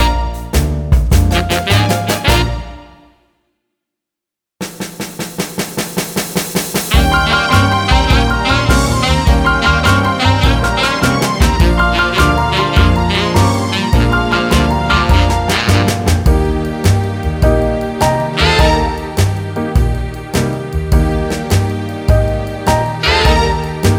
Minus Saxes Jazz / Swing 3:12 Buy £1.50